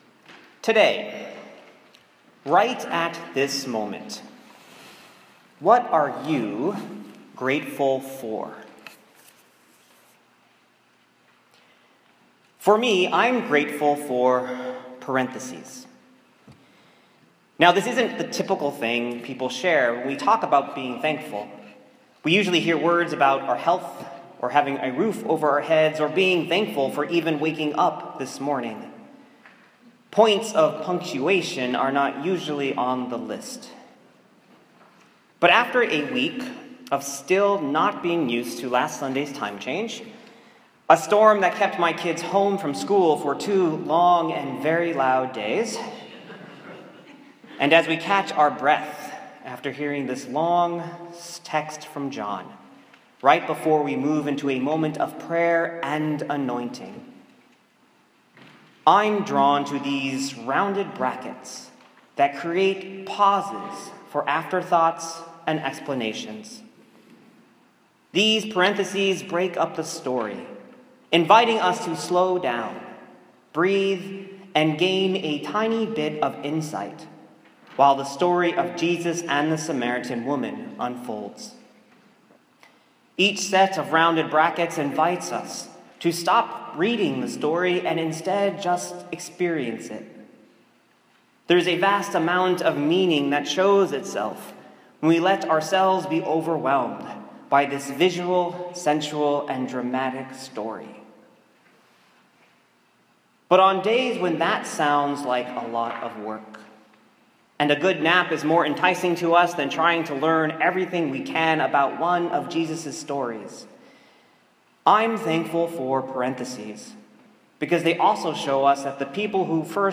Get Me a Drink: a sermon on sharing even when you don’t know the full story.